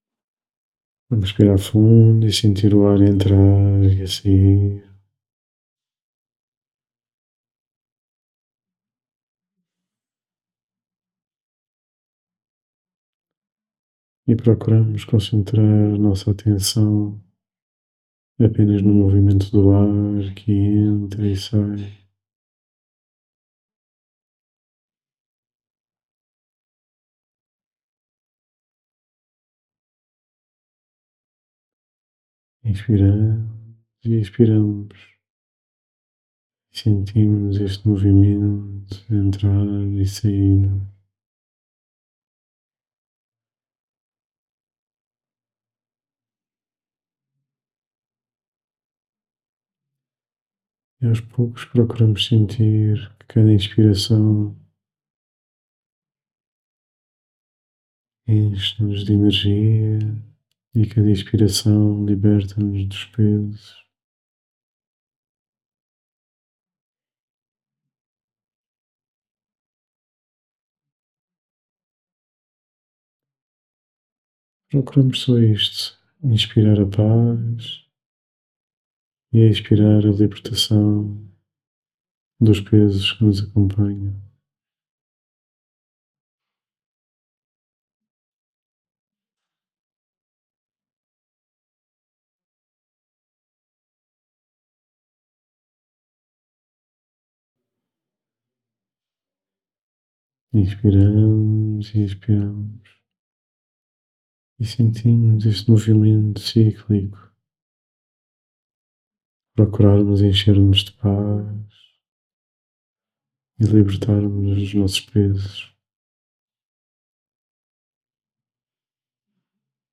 Meditação